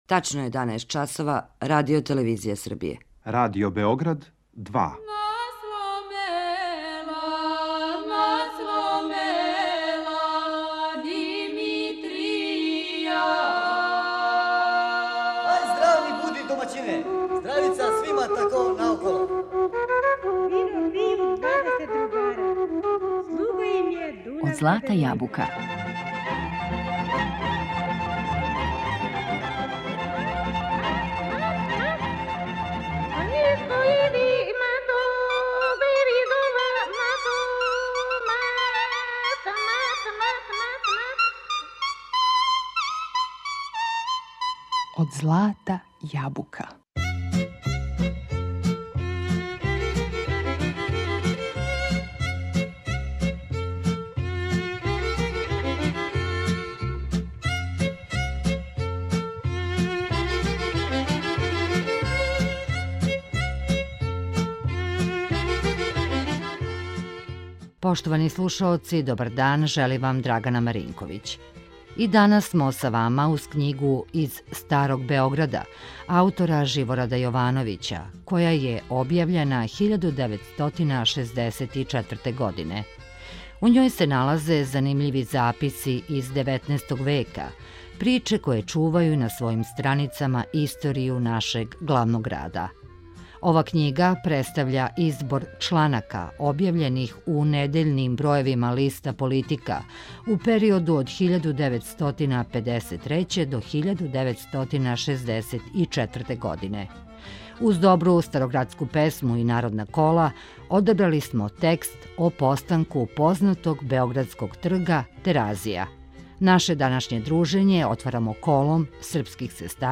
U njoj se nalaze zanimljivi zapisi iz 19. veka, priče koje čuvaju na svojim stranicama istoriju našeg glavnog grada. Uz dobru starogradsku pesmu i narodna kola, odabrali smo tekst o postanku Terazija.